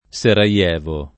Seraievo [ S era L$ vo ] → Sarajevo